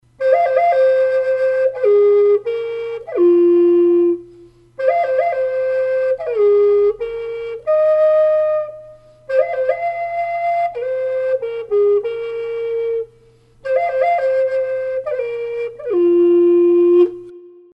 Пимак F Тональность: F
Данная модель изготовлена из древесины ольхи.В комплекте чехол, аппликатура и небольшое нотное приложение.